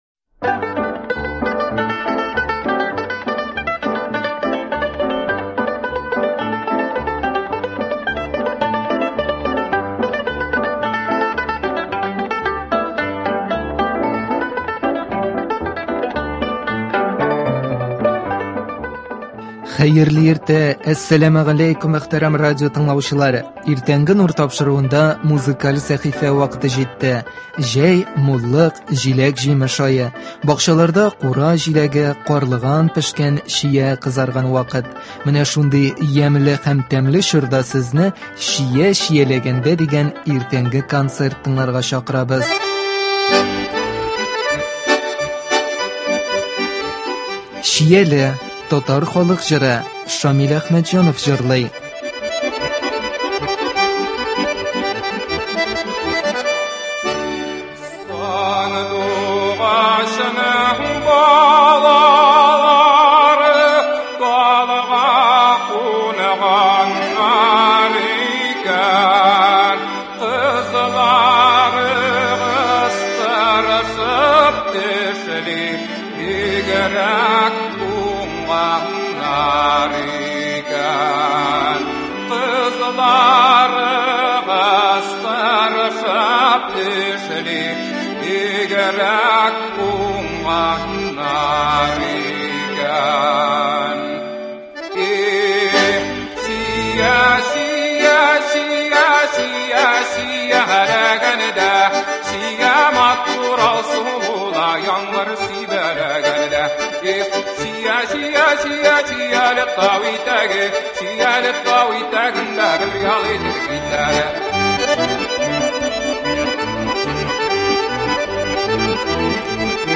Менә шундый ямьле һәм тәмле чорда сезне “Чия чияләгәндә” дигән иртәнге концерт тыңларга чакырабыз.